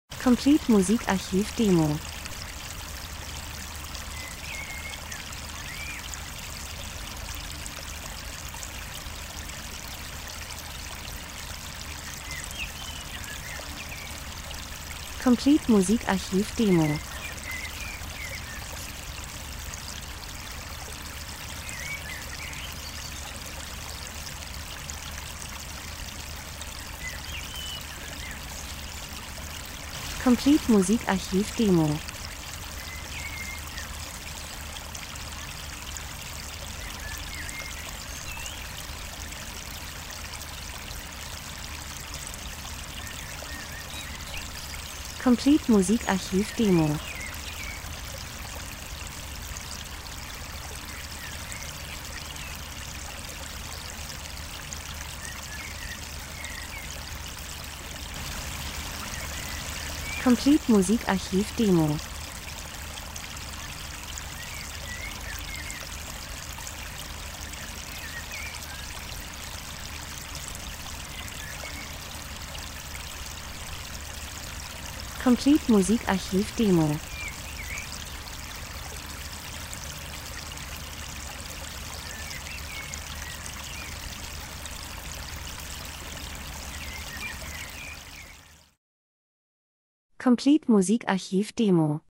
Frühling -Geräusche Soundeffekt Natur Vögel Wind Wasser 01:31